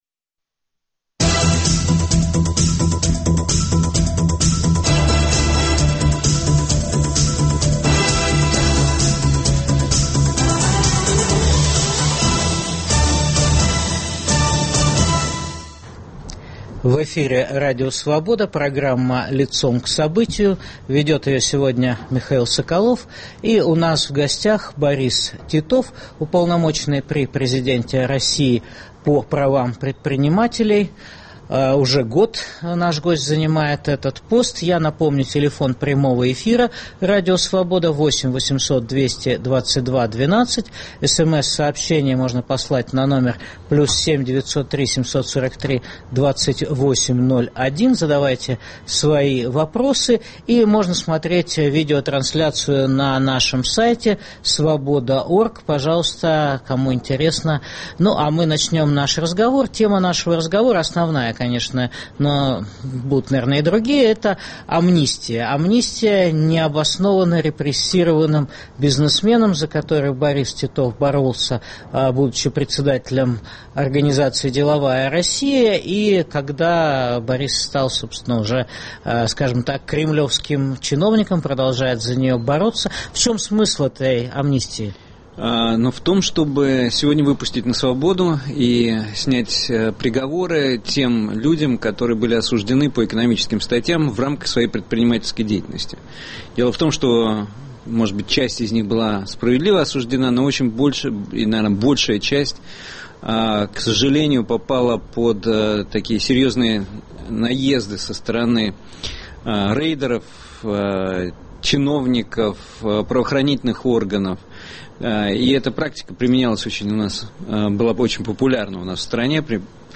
Что нужно российскому обществу: амнистия для предпринимателей или для всех осужденных? В программе выступит уполномоченный при президенте России по правам предпринимателей Борис Титов.